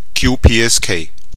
qpsk.ogg